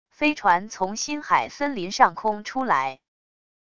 飞船从新海森林上空出来wav音频